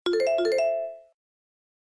remind.wav